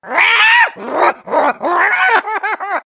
One of Diddy Kong's voice clips in Mario Kart Wii